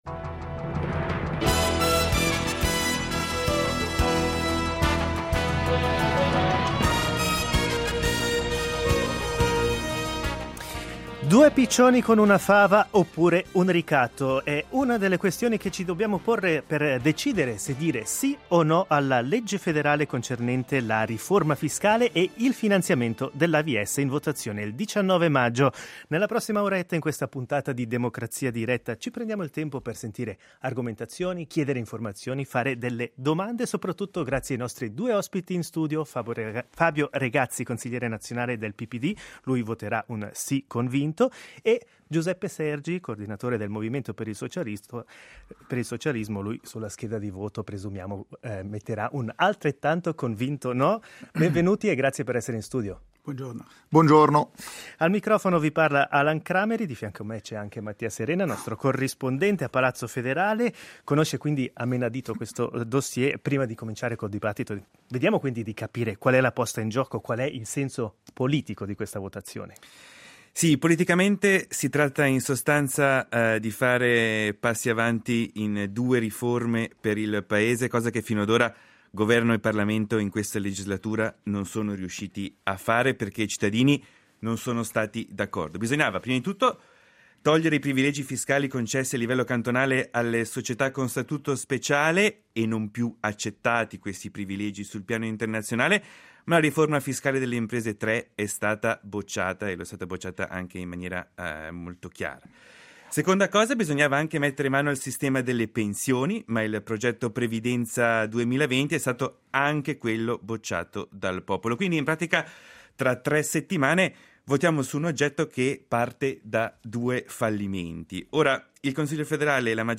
Domande che riguardano tutti noi e sulle quali si confronteranno a Democrazia diretta.